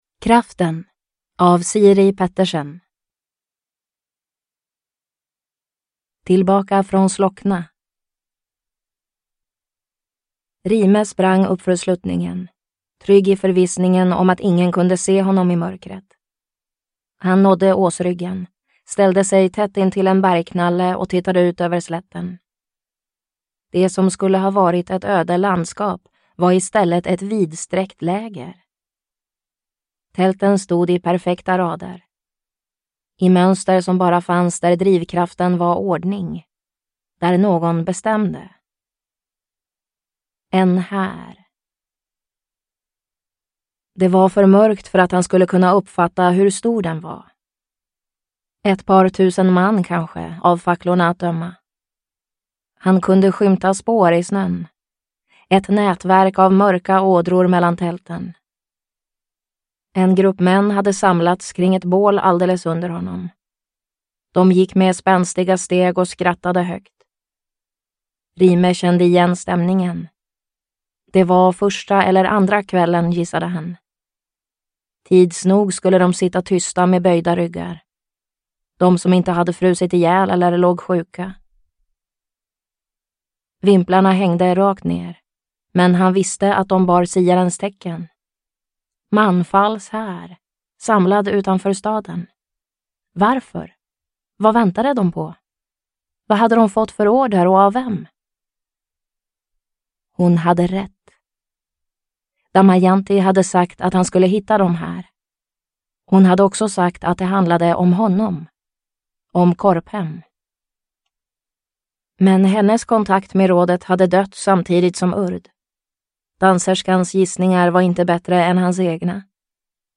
Kraften – Ljudbok – Laddas ner